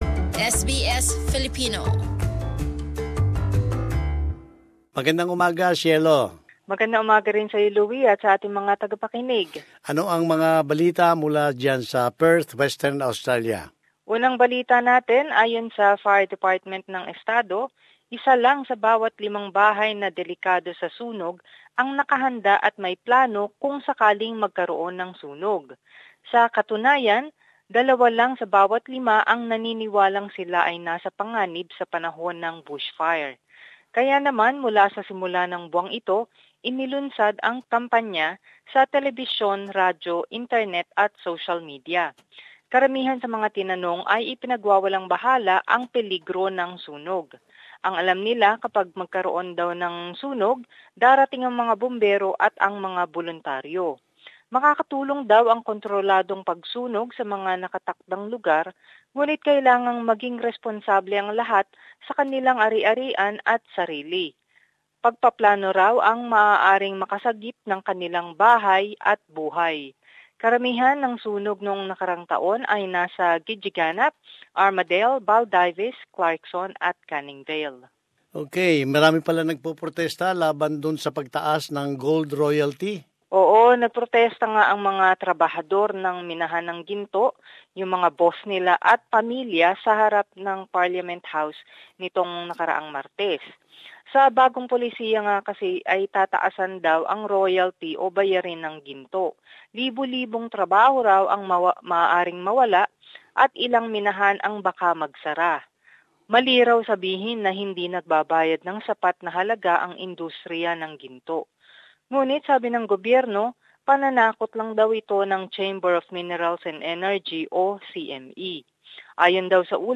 Latest news from Western Australian